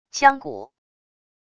锵鼓wav音频